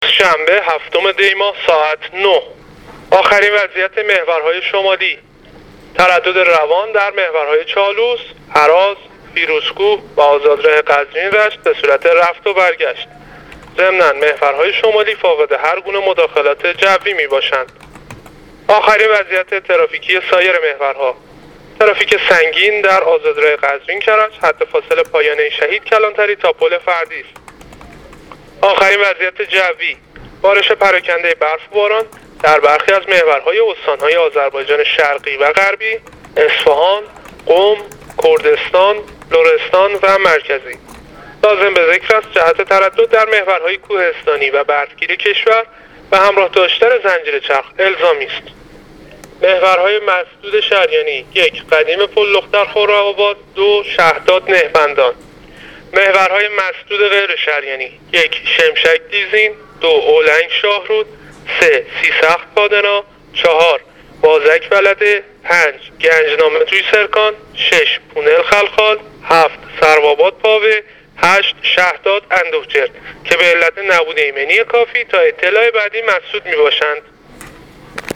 گزارش رادیو اینترنتی از آخرین وضعیت ترافیکی جاده‌ها تا ساعت۹ هفتم دی ۱۳۹۸: